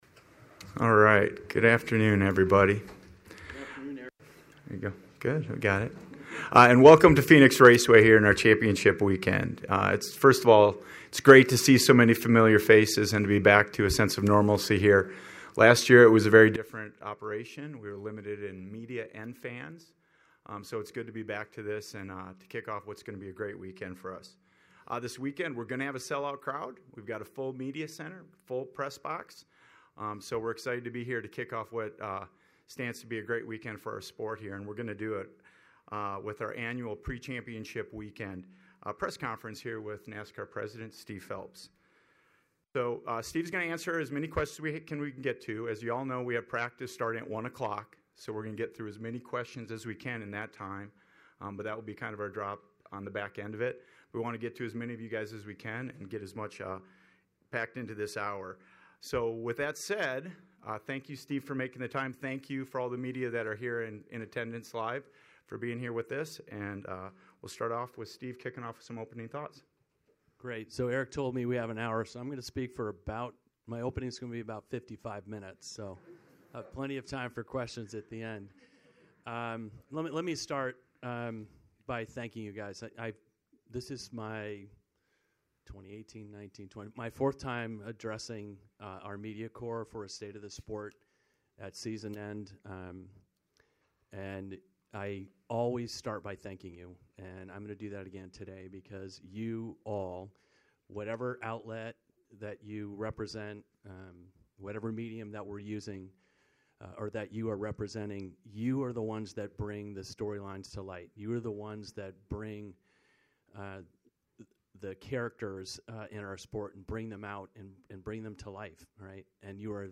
PhelpsMediaAvailability11.5.mp3